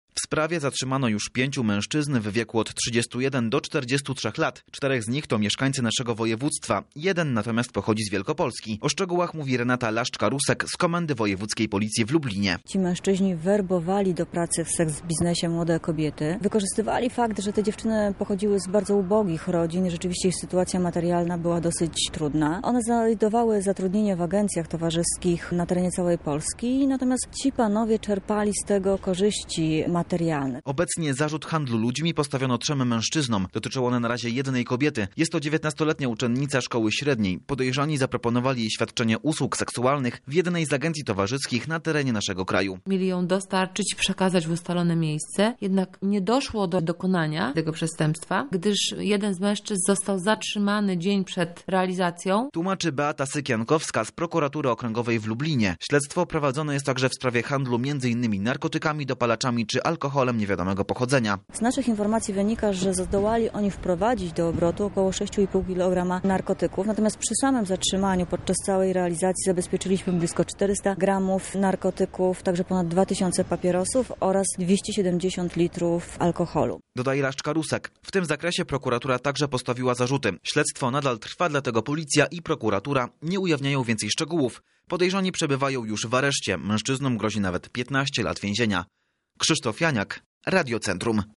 Szczegóły poznał nasz reporter